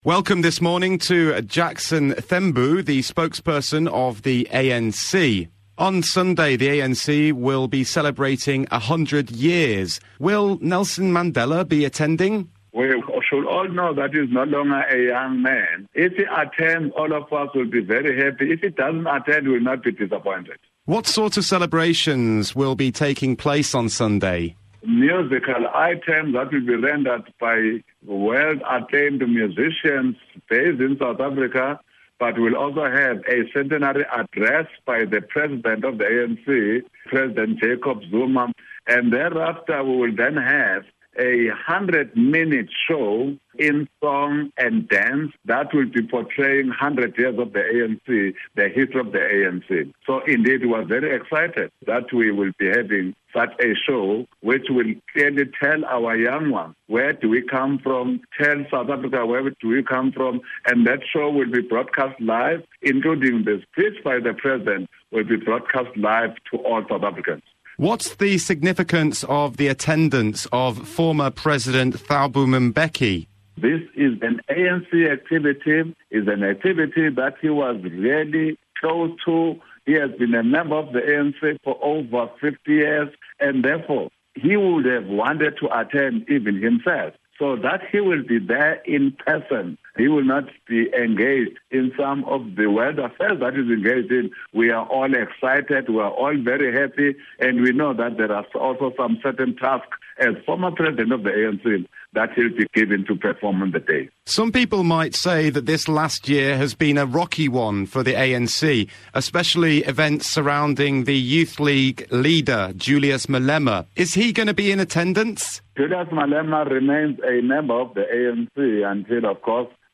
Interview: ANC Spokesperson Jackson Mthembu
anc-centenary-interview-about-100-year-anniversary-on-rfi_english-spokesman-jackson-mthembu-myanc_.mp3